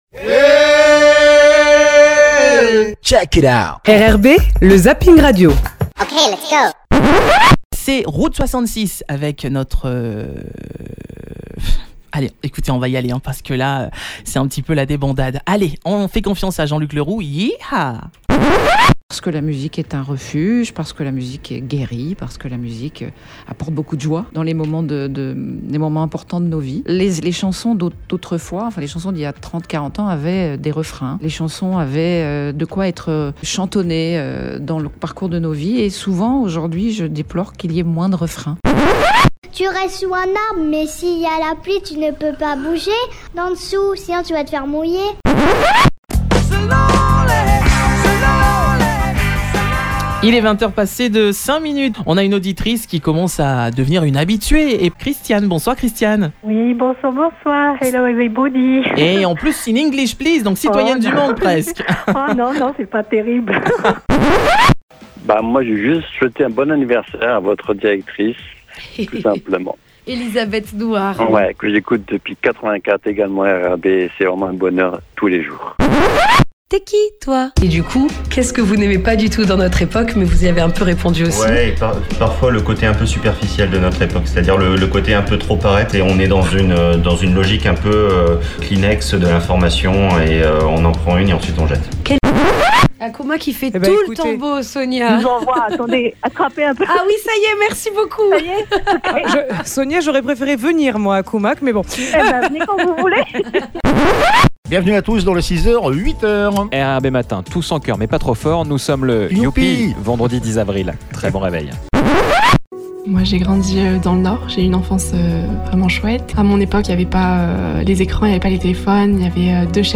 OU PRESQUE" LE ZAPPING RADIO ZAPPING RADIO 17-04-06 "PARCOURS SANS HIC …